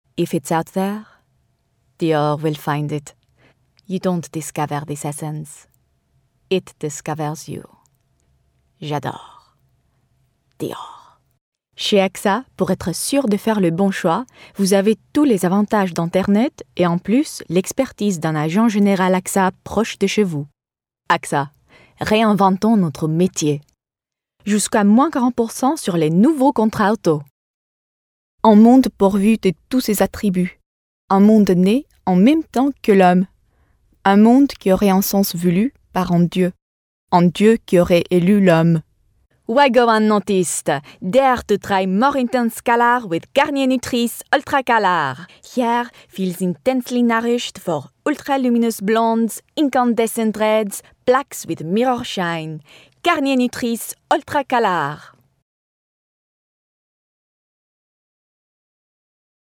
French Montage: